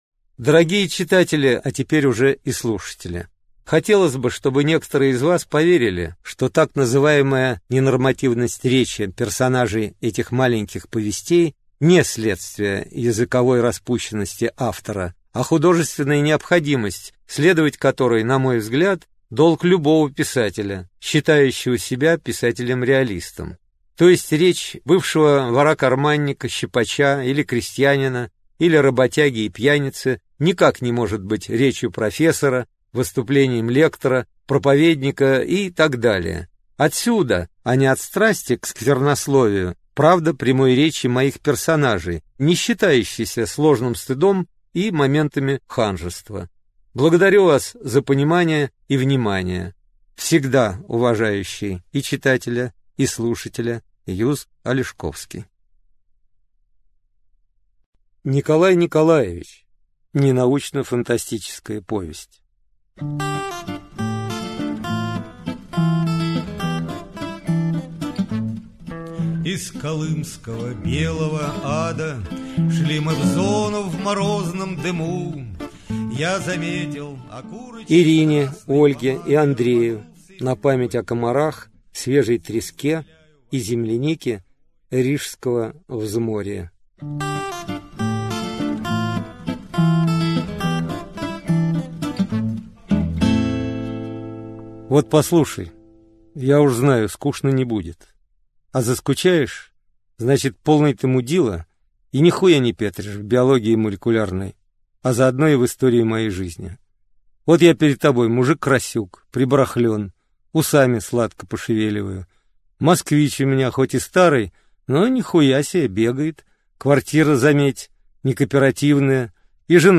Аудиокнига Николай Николаевич | Библиотека аудиокниг
Aудиокнига Николай Николаевич Автор Юз Алешковский Читает аудиокнигу Юз Алешковский.